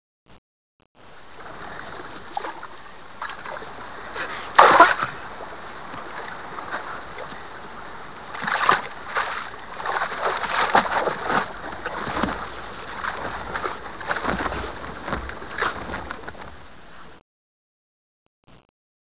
WATER.WAV